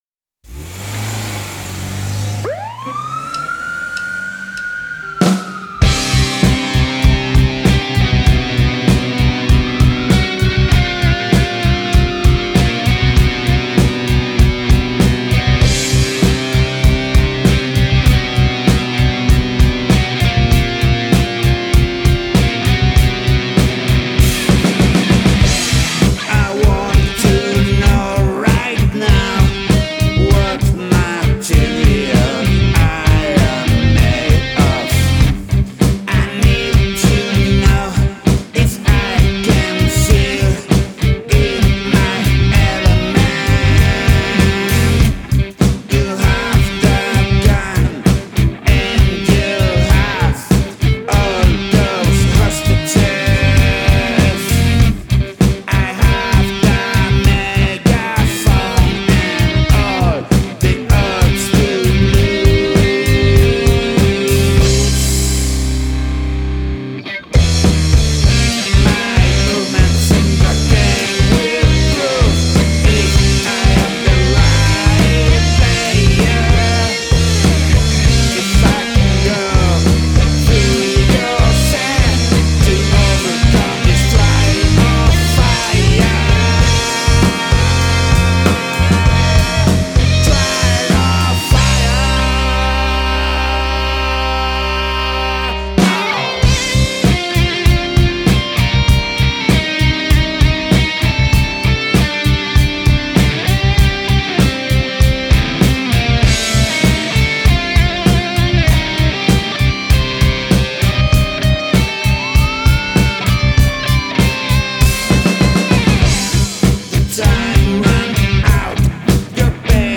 Incorporate sirens